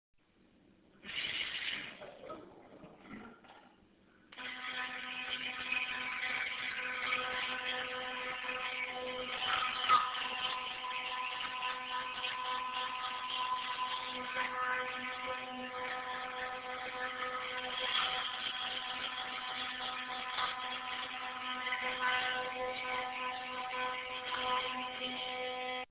Field Recording
Location: Pembroke bathroom 9:50am
Sounds heard: running water in sink, drain, electric toothbrush
Brushing-Teeth.mp3